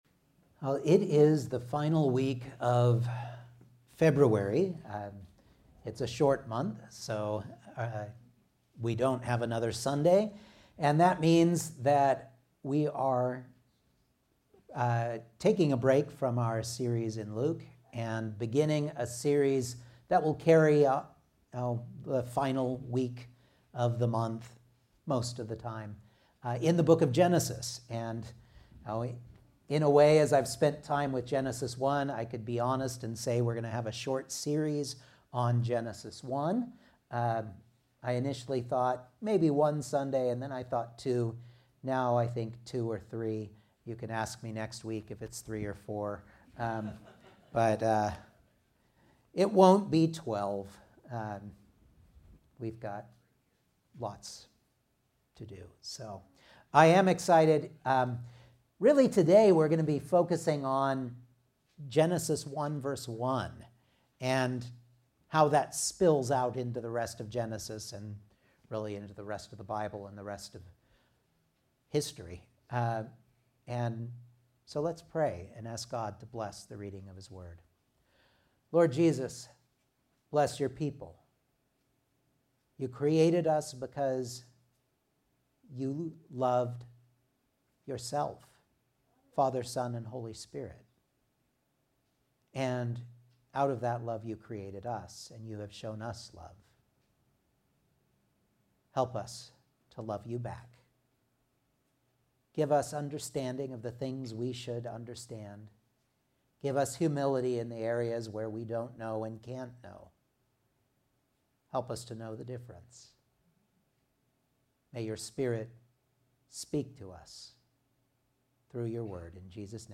Genesis 1 Service Type: Sunday Morning Outline